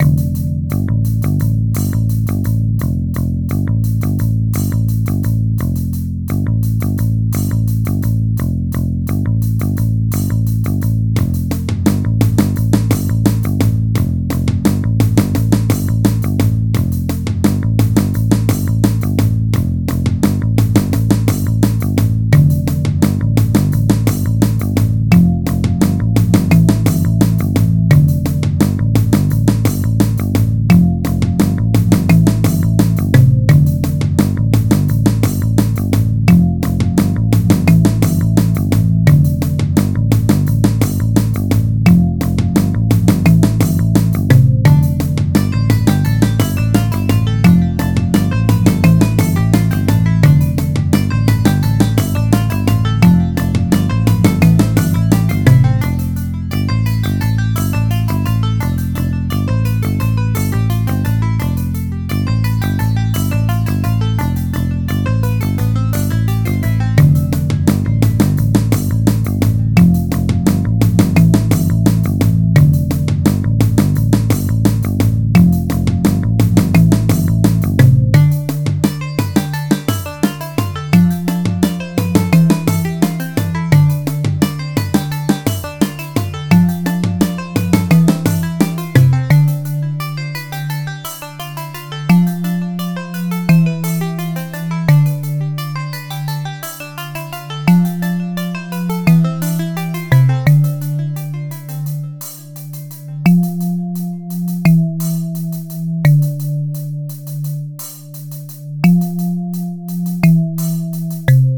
Walkable music